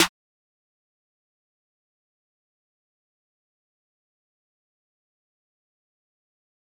snare 6 .wav